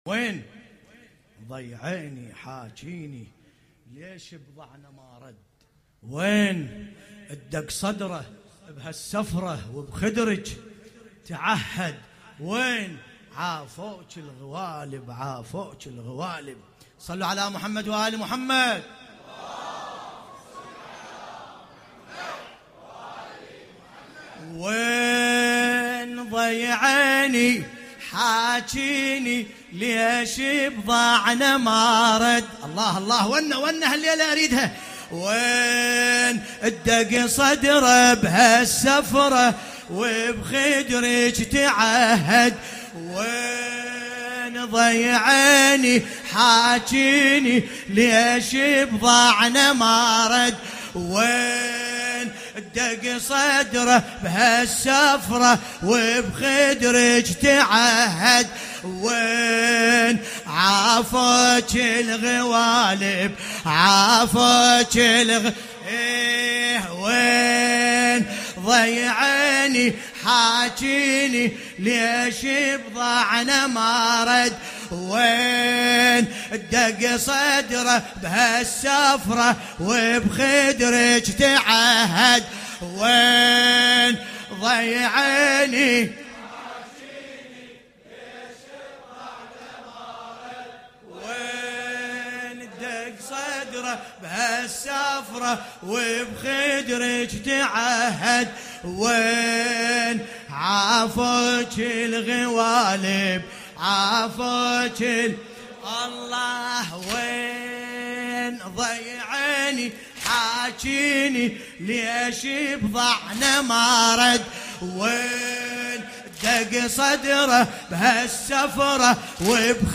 ملف صوتی وين ضي عيني بصوت باسم الكربلائي